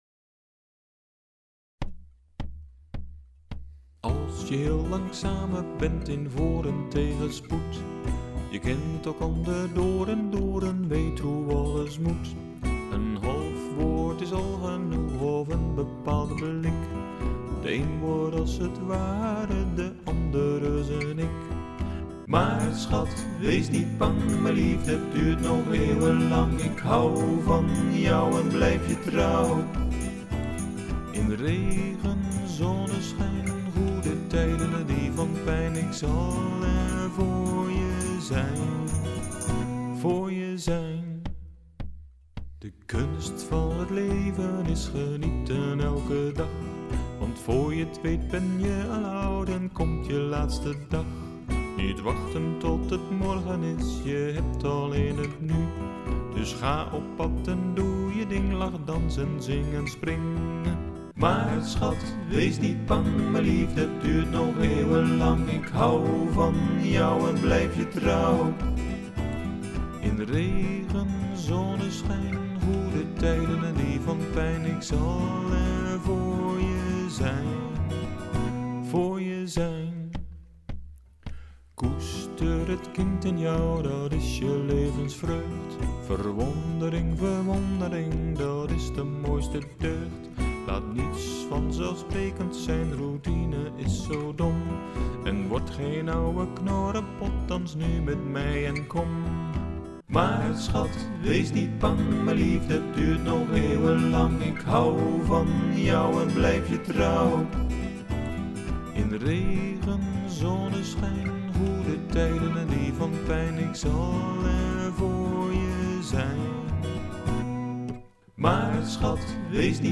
De frisbee wordt als een soort trom gebruikt…..
De Folk Song:
Folk112.mp3